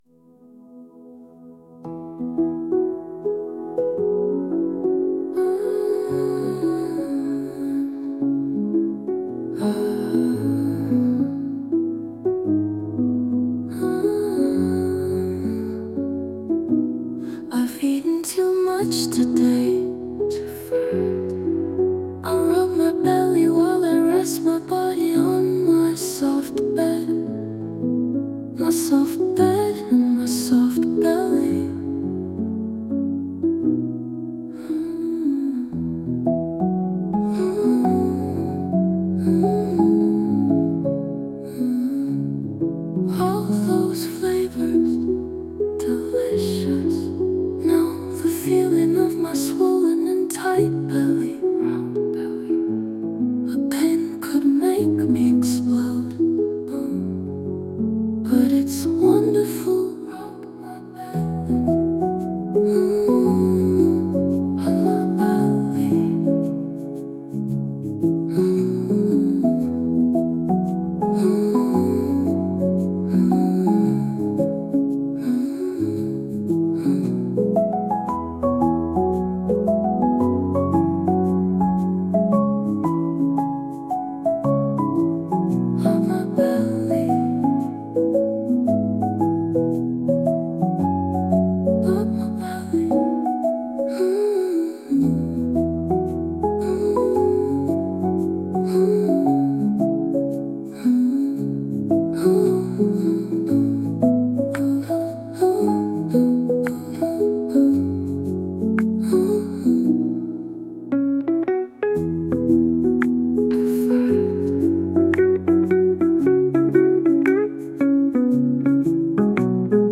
Now on the Sleep screen, this pleasant music will play!